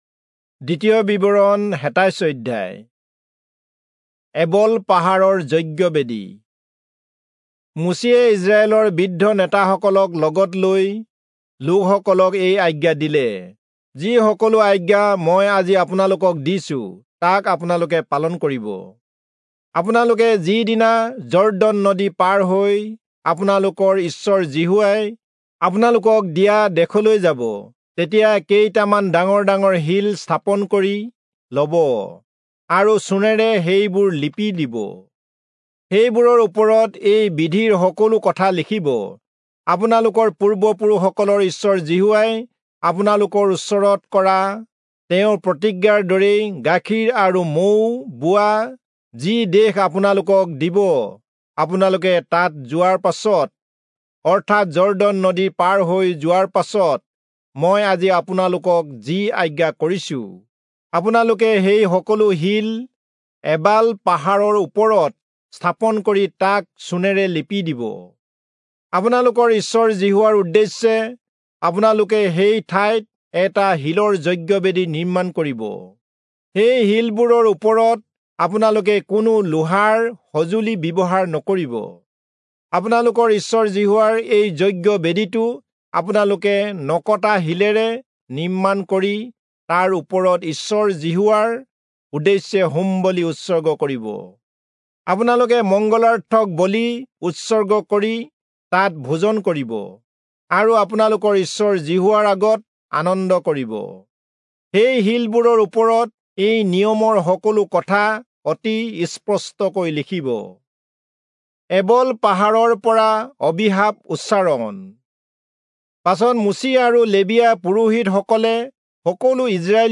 Assamese Audio Bible - Deuteronomy 15 in Hov bible version